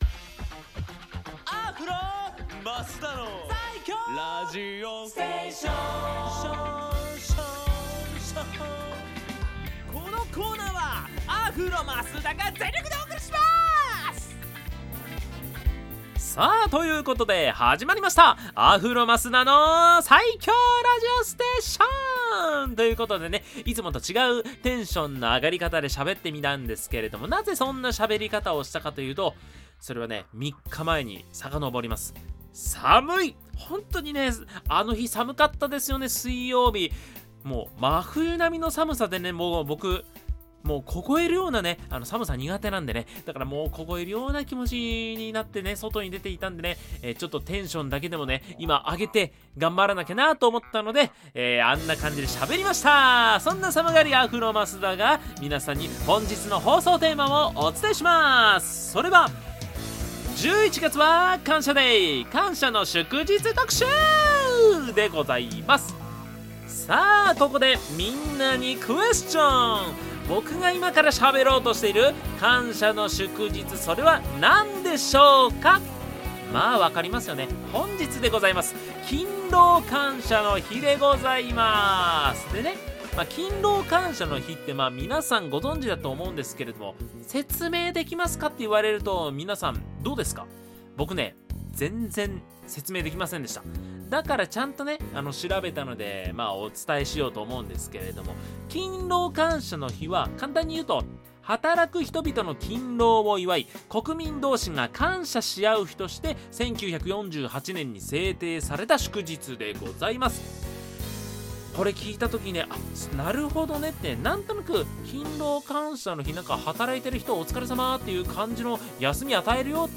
こちらのブログでは、FM83.1Mhzレディオ湘南にて放送されたラジオ番組「湘南MUSICTOWN Z」内の湘南ミュージックシーンを活性化させる新コーナー！
こちらが放送音源です♪